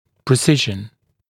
[prɪ’sɪʒn][при’сижн]точность, точный